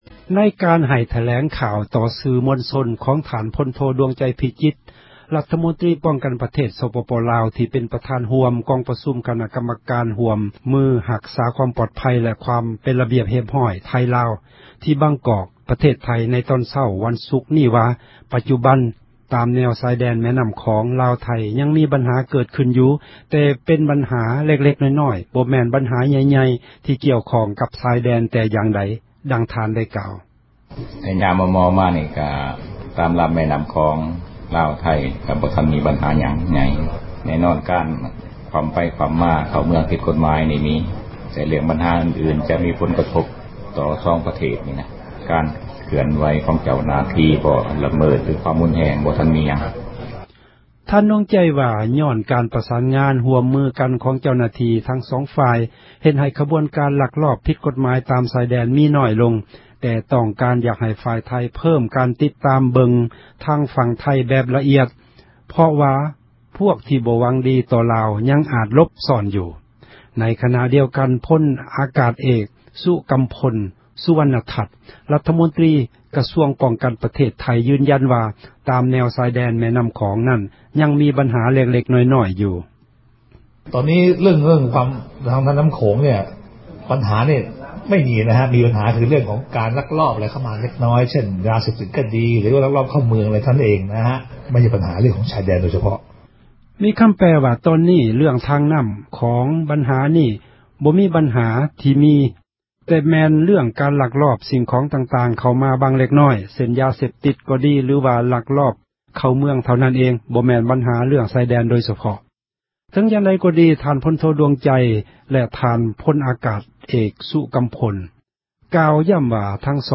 ໃນການໃຫ້ ຖແລງຂ່າວ ຕໍ່ສື່ມວນຊົນ ຂອງ ທ່ານ ພົນໂທ ດວງໃຈພິຈິດ ຣັຖມົນຕຣີ ປ້ອງກັນປະເທດ ສປປ ລາວ ທີ່ເປັນ ປະທານຮ່ວມ ກອງປະຊຸມ ຄນະກັມມະການ ຮ່ວມມື ຮັກສາ ຄວາມປອດພັຍ ແລະ ຄວາມ ເປັນລະບຽບ ຮຽບຮ້ອຍ ໄທ-ລາວ ທີ່ບາງກອກ ປະເທດໄທ ໃນຕອນເຊົ້າ ວັນສຸກນີ້ວ່າ: ປັດຈຸບັນ ຕາມຊາຍແດນ ແມ່ນໍ້າຂອງ ລາວ-ໄທ ຍັງມີບັນຫາ ເກີດຂື້ນຢູ່ ແຕ່ເປັນບັນຫາ ເລັກໆນ້ອຍໆ ບໍ່ແມ່ນບັນຫາ ໃຫ່ຽໆ ທີ່ກ່ຽວຂ້ອງ ກັບຊາຍແດນ ແຕ່ຢ່າງໃດ.